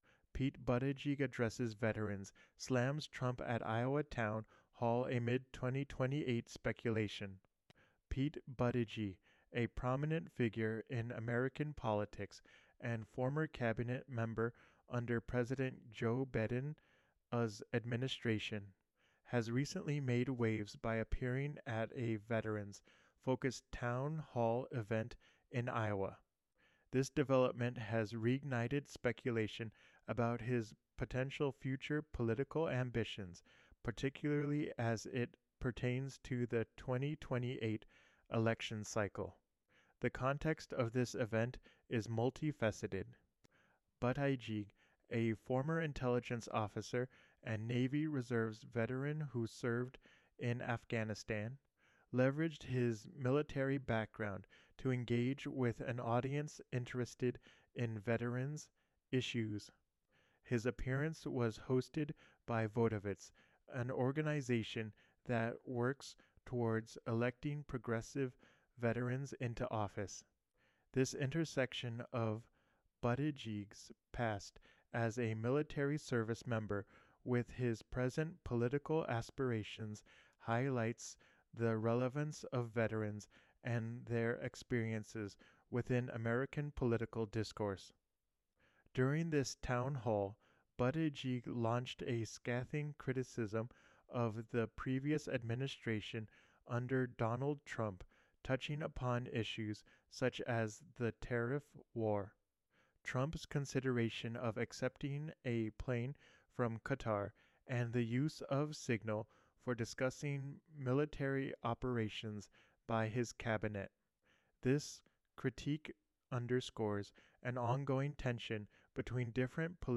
Thupster Cast Pete Buttigieg Addresses Veterans